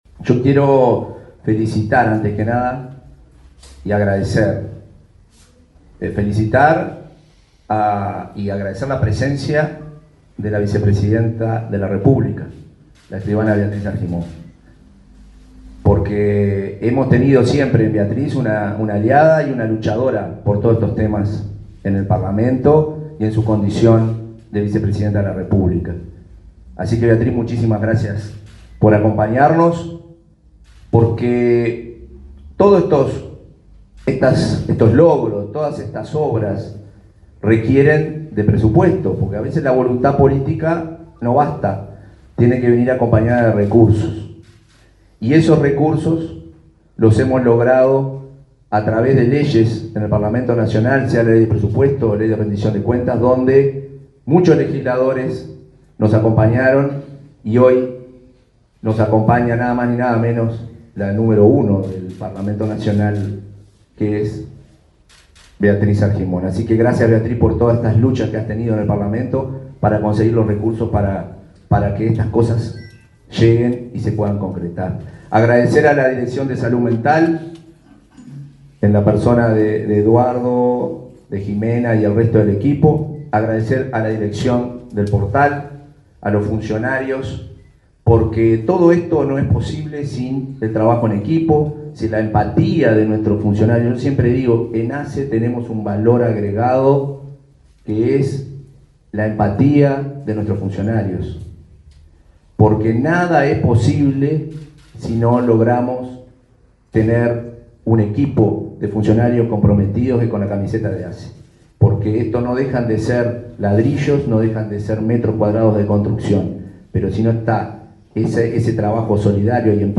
Palabra de autoridades en acto en Portal Amarillo